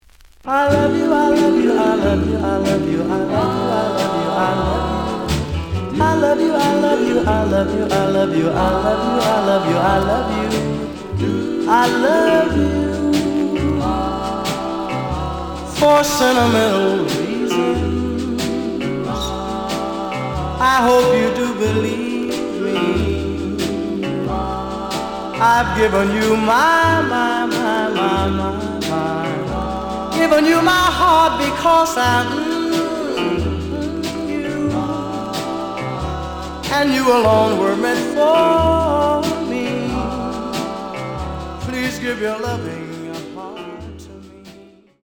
The audio sample is recorded from the actual item.
●Genre: Rhythm And Blues / Rock 'n' Roll
Looks good, but slight noise on both sides.)